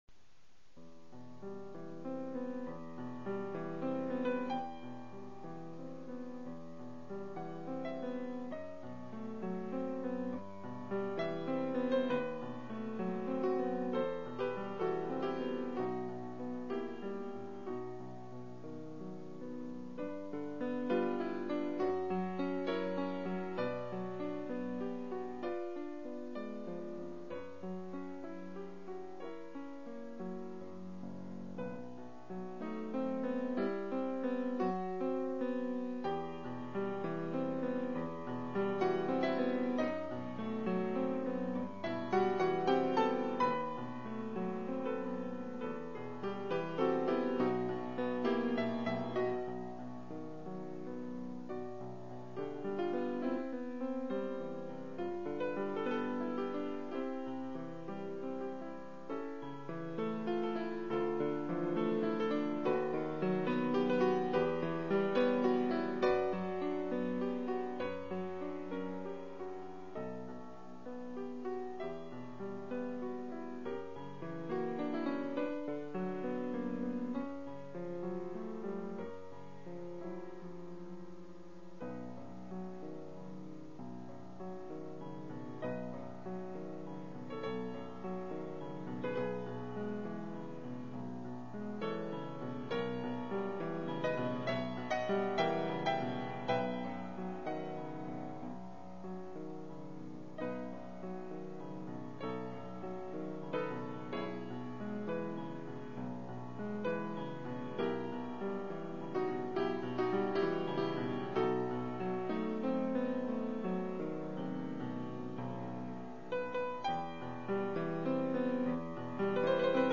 Nocturne in E Minor